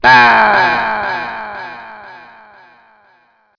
voice_aw_aw_aw.wav